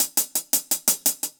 Index of /musicradar/ultimate-hihat-samples/170bpm
UHH_AcoustiHatB_170-04.wav